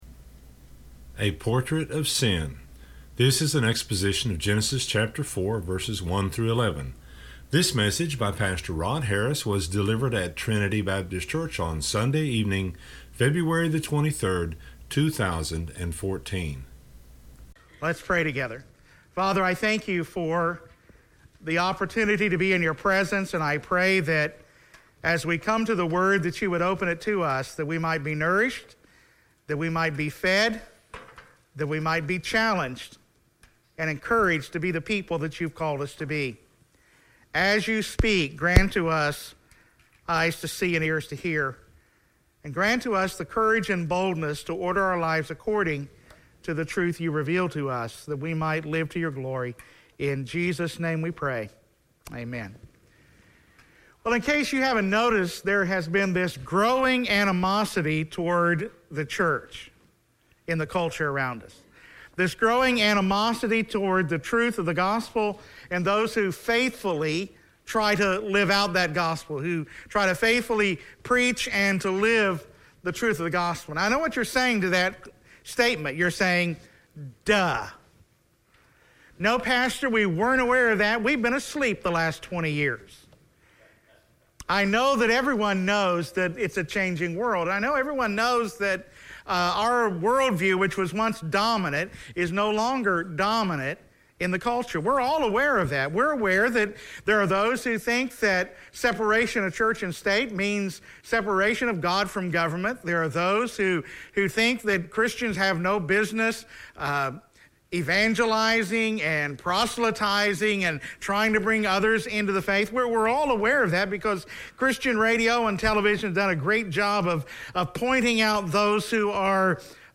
This exposition of Genesis 4:1-11